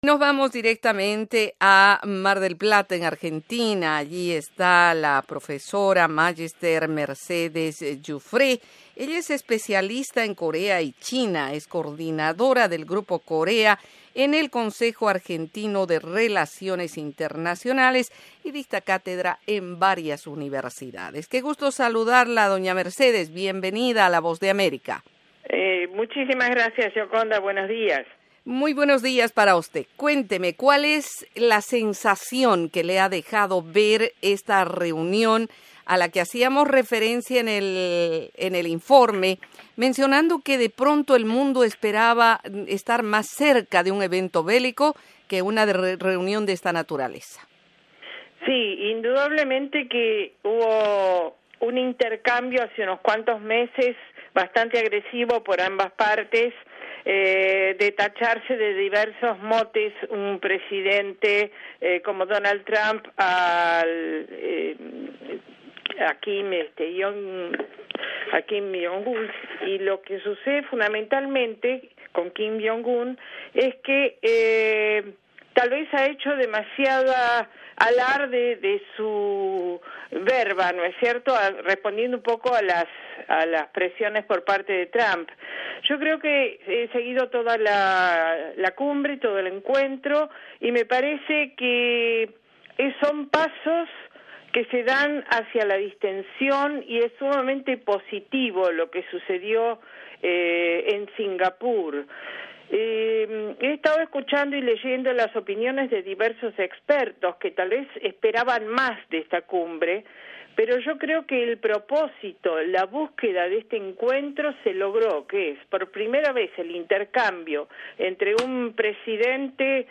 La Voz de América analiza el tema en esta entrevista con la experta en temas de Corea